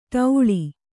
♪ ṭauḷi